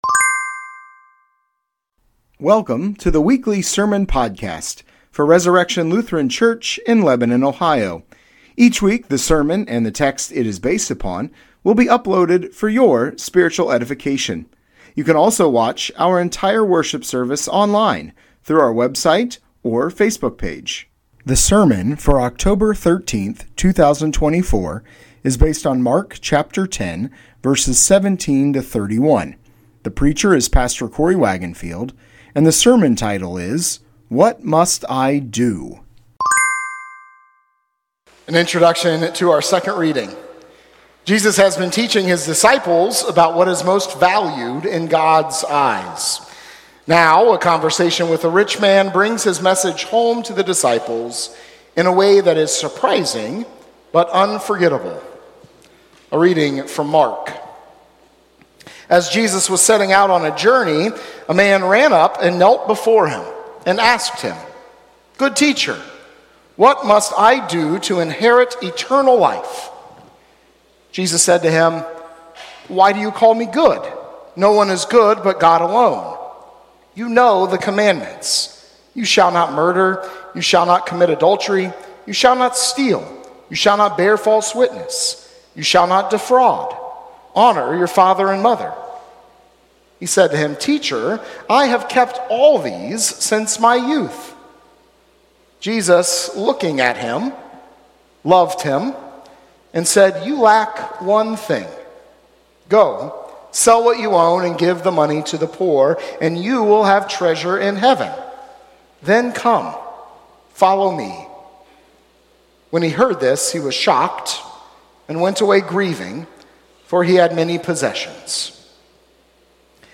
Sermon Podcast Resurrection Lutheran Church - Lebanon, Ohio October 13, 2024 - "What Must I Do?"